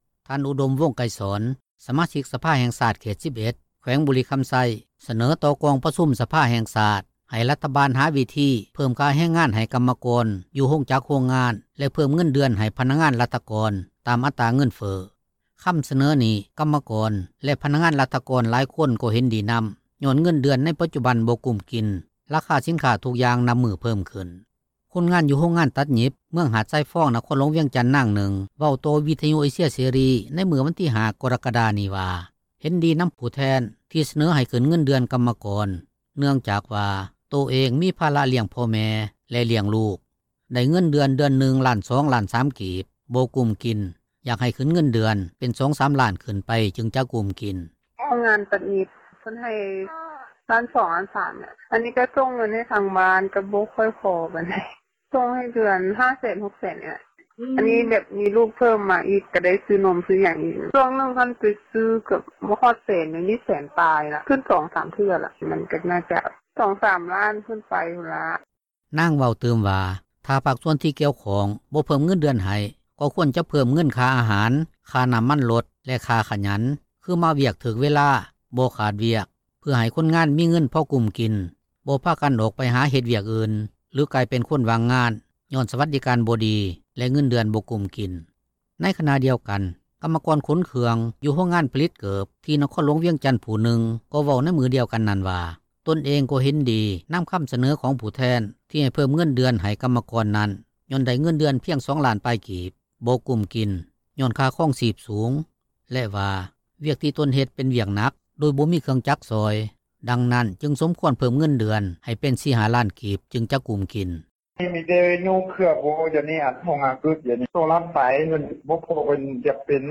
ຄົນງານຢູ່ໂຮງງານຕັດຫຍິບ ເມືອງຫາດຊາຍຟອງ ນະຄອນຫລວງວຽງຈັນ ນາງນຶ່ງເວົ້າຕໍ່ວິທຍຸ ເອເຊັຽ ເສຣີ ໃນມື້ວັນທີ 5 ກໍຣະກະດານີ້ວ່າ ເຫັນດີນໍາຜູ້ແທນ ທີ່ສເນີໃຫ້ຂຶ້ນເງິນເດືອນ ກັມມະກອນ ເນື່ອງຈາກວ່າ ໂຕເອງມີພາຣະລ້ຽງພໍ່ແມ່ ແລະລ້ຽງລູກ, ໄດ້ເງິນເດືອນ ເດືອນນຶ່ງລ້ານສອງ, ລ້ານສາມ ກີບ, ບໍ່ກຸ້ມກິນ, ຢາກໃຫ້ຂຶ້ນເງິນເດືອນເປັນ 2-3 ລ້ານ ຂຶ້ນໄປຈຶ່ງຈະກຸ້ມກິນ.